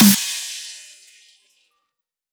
SMANS_Snare_Full.wav